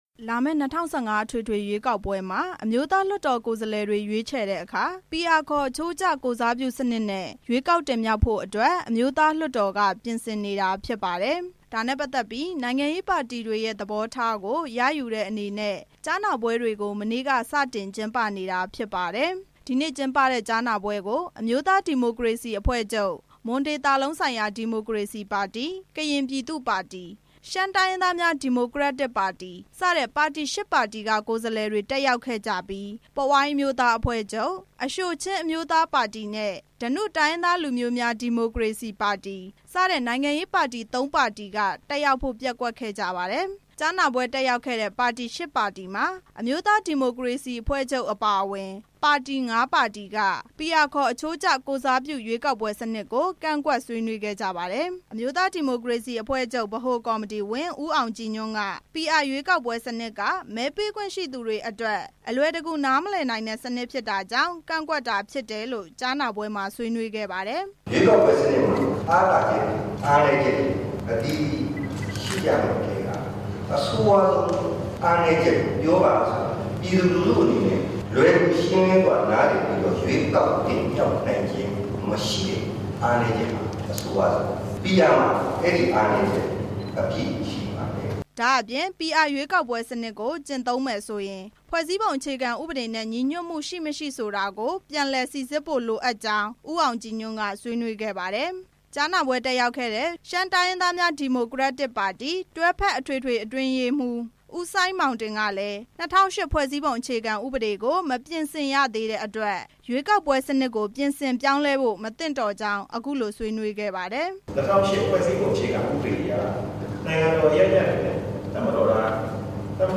နိုင်ငံရေးပါတီတွေရဲ့ ဆွေးနွေးချက်တချို့